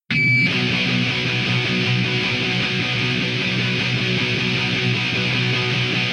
Auf einem längst vergessenen Musikblog hab ich ab und zu ein kurzes, markantes Riff gepostet.